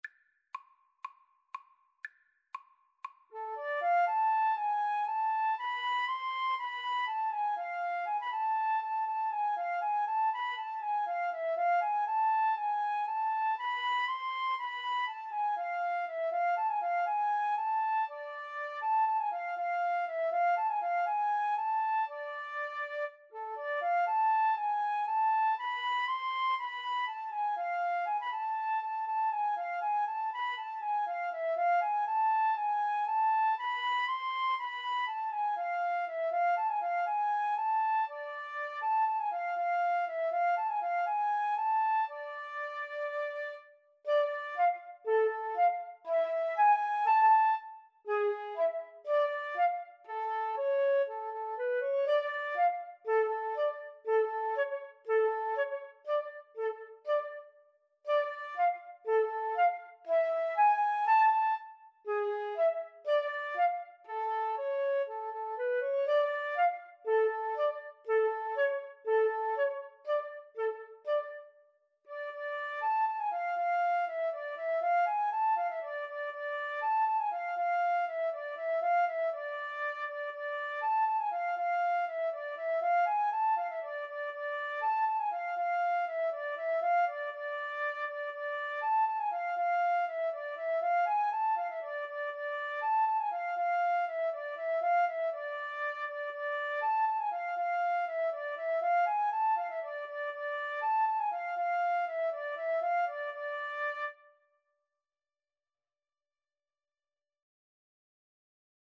Odessa Bulgarish is a traditional klezmer piece originating in the Ukraine.
4/4 (View more 4/4 Music)
C major (Sounding Pitch) (View more C major Music for Flute Duet )